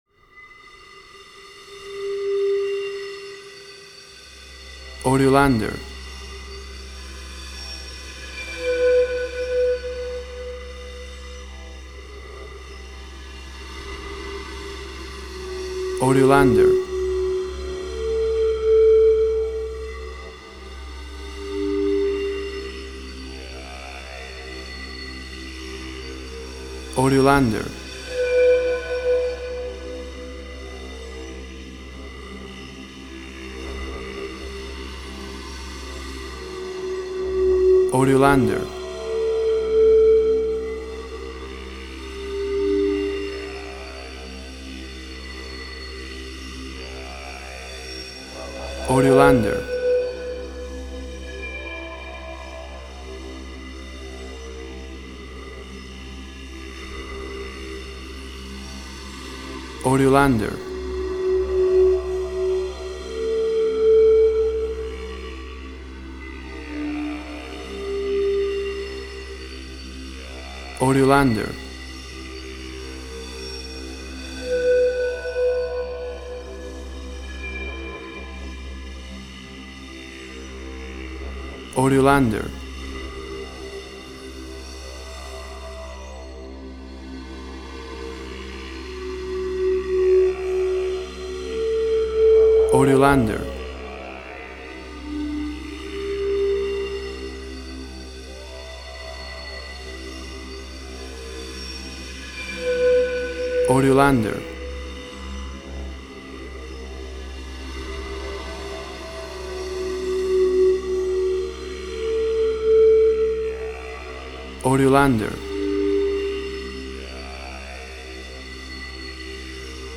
WAV Sample Rate: 24-Bit stereo, 48.0 kHz